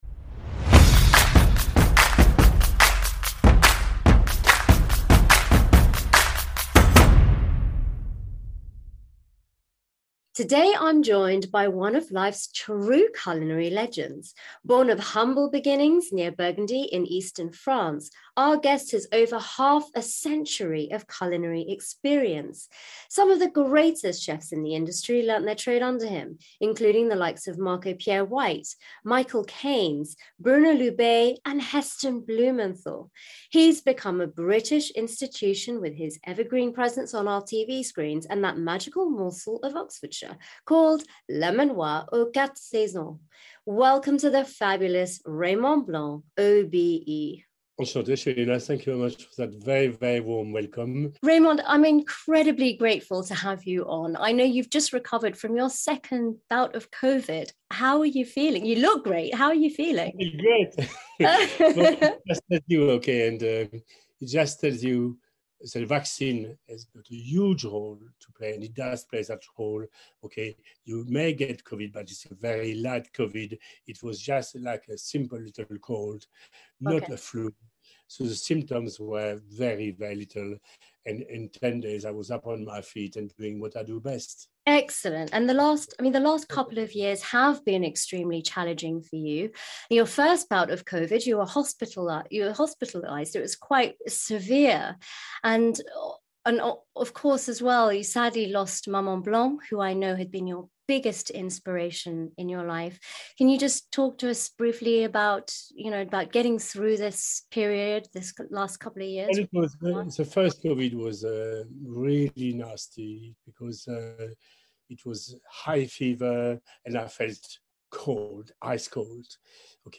It’s the legendary Raymond Blanc OBE & I’m so excited to share part 1 of 2 from this amazing interview with you guys! Raymond dips in and out of his past sharing the most wonderful stories from his childhood and memories of Maman Blanc.